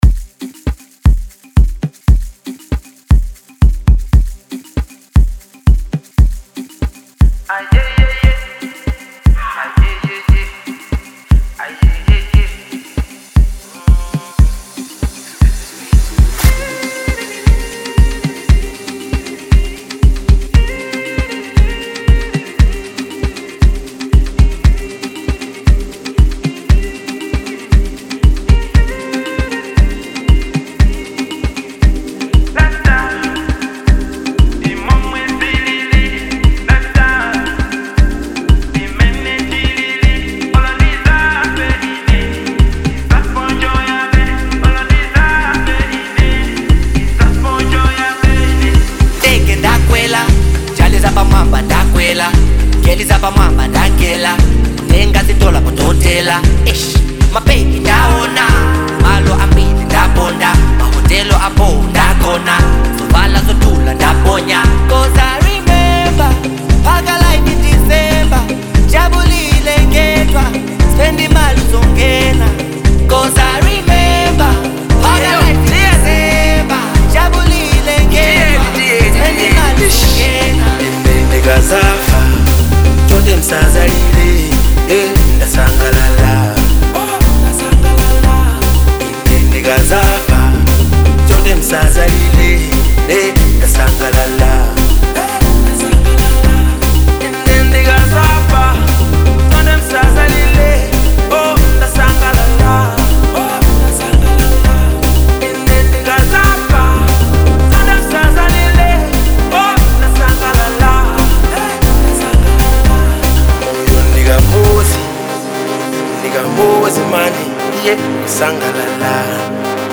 Genre 3 Step